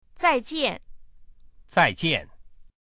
zaijian.mp3